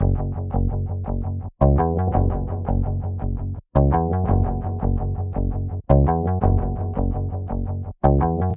噪声
描述：fx循环
Tag: 120 bpm Ambient Loops Fx Loops 954.13 KB wav Key : Unknown